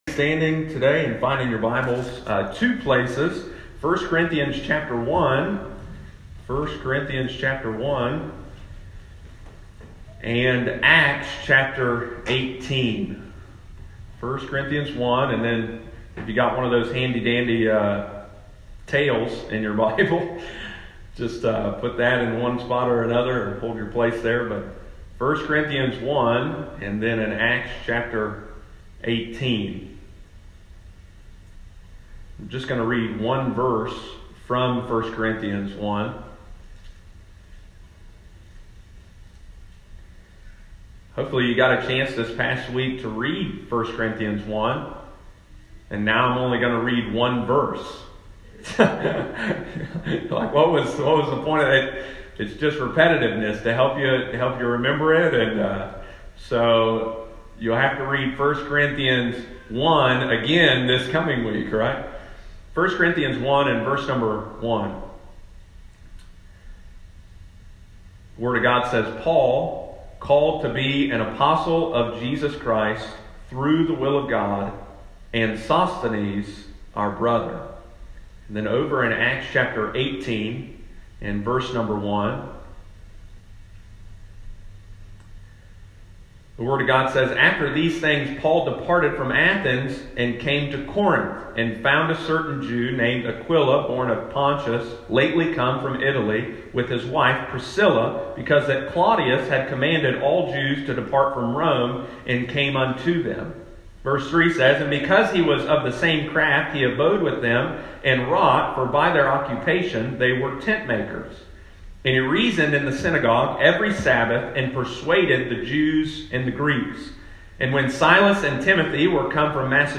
on Sunday morning